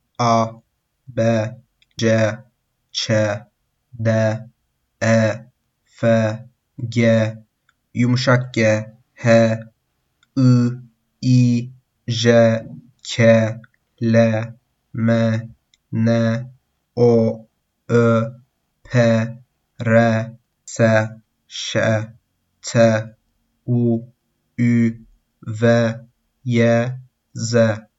Hear how to pronounce the Turkish alphabet:
alphabet_turkish-lat.mp3